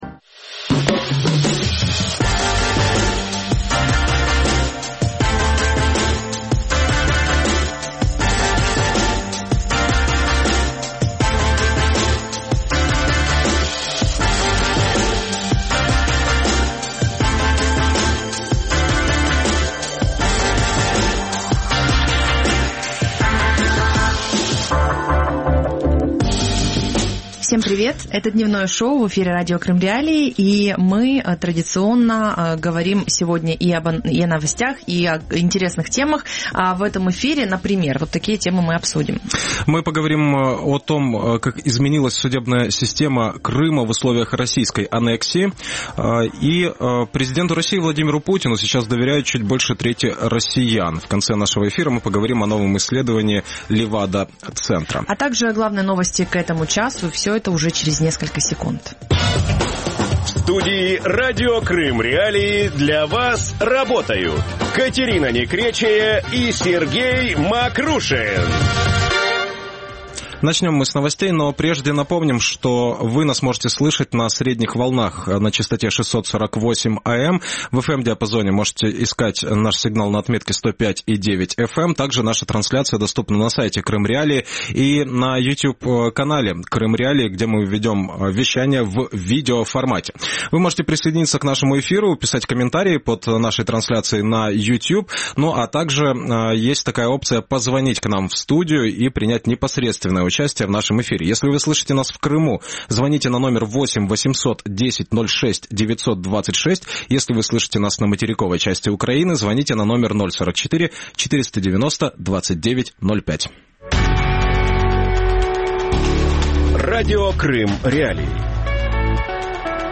Кто судит крымчан? | Дневное ток-шоу